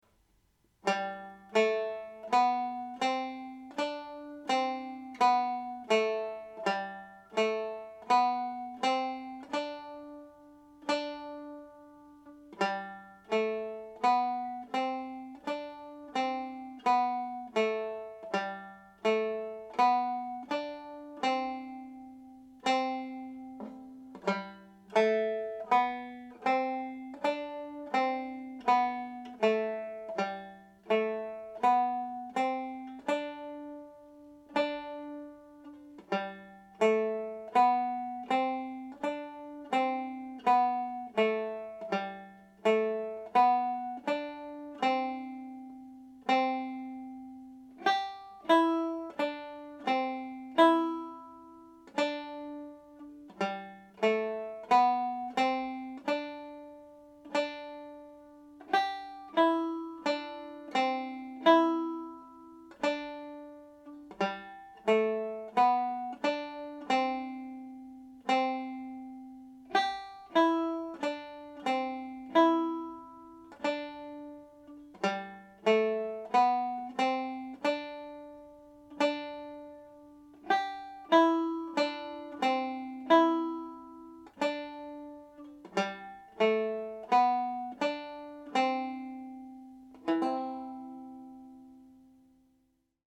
I’ve based this tutorial around learning the G major scale on the tenor banjo and mandolin.
Andy Reids Polka played slowly
andy-reids-polka_slow.mp3